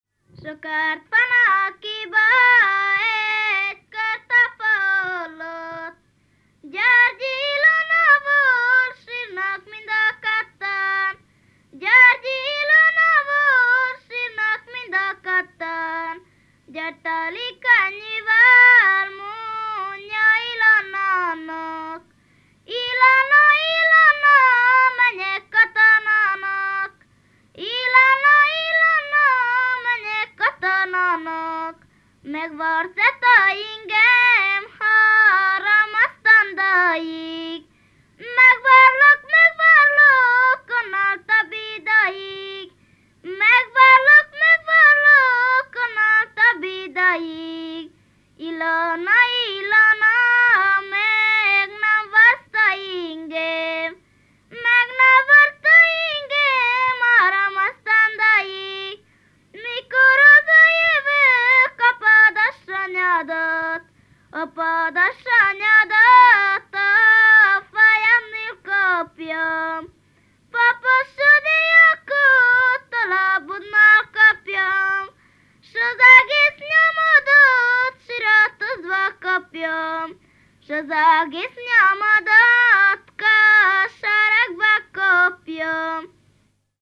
ének
ballada
Lészped
Moldva (Moldva és Bukovina)